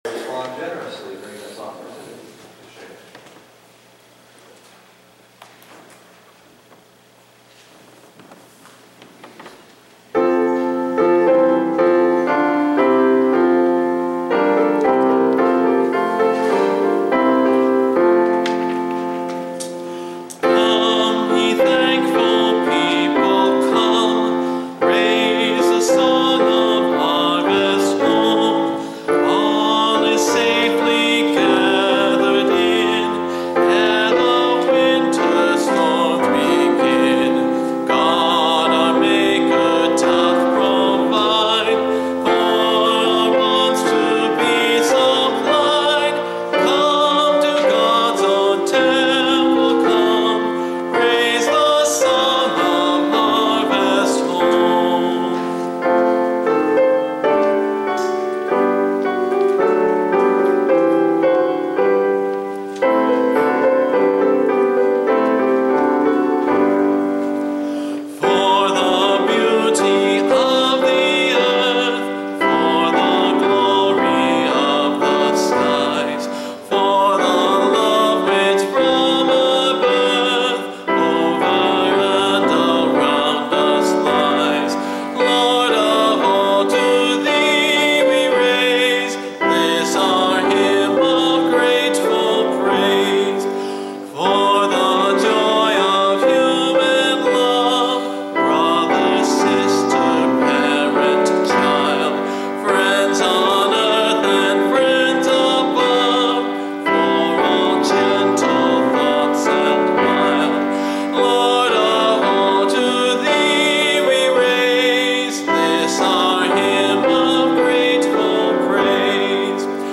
Here is the lyric and sound tracks for the Medley “With a Song of Thanksgiving” that I sang Sunday at Avondale United Methodist Church.